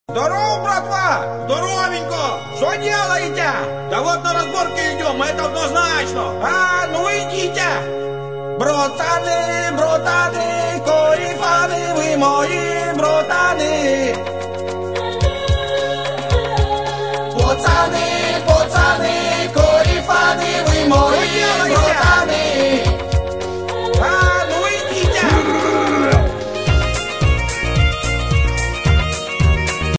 Небольшая нарезка, так себе.